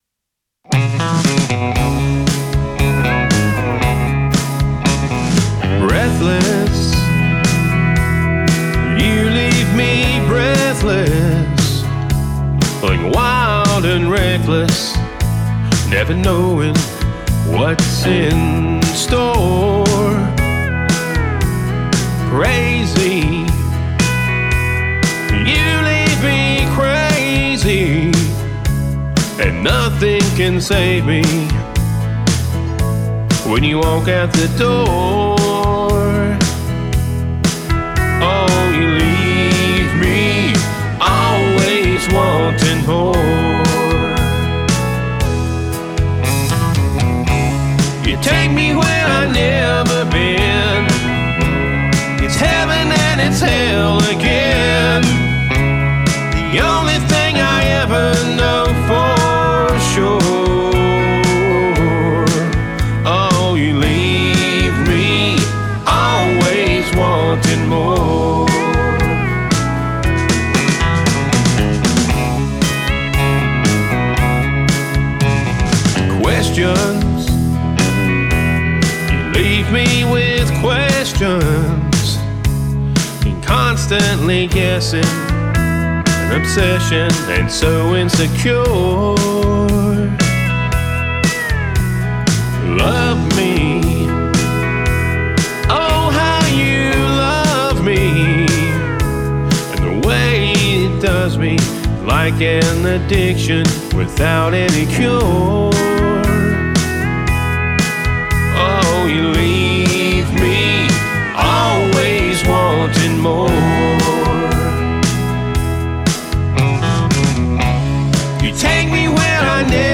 heartfelt country love songs
have released a fresh new mix